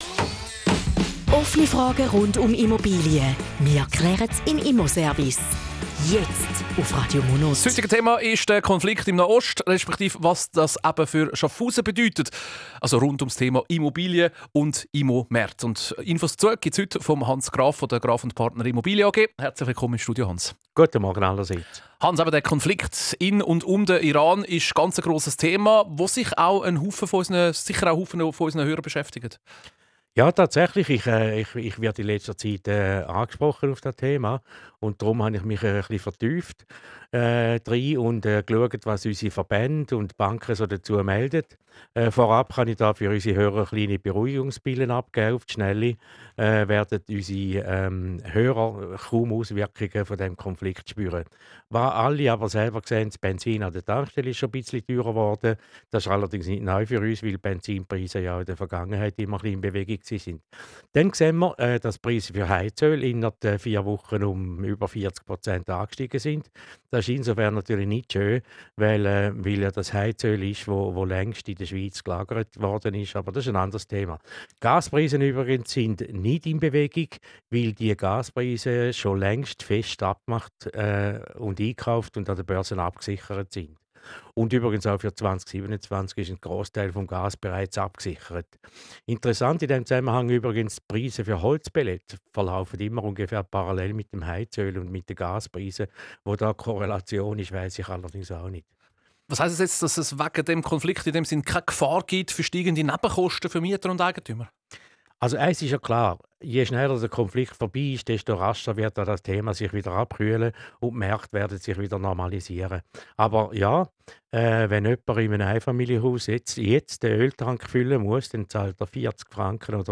Zusammenfassung des Interviews zum Thema "Einfluss Nahostkonflikt":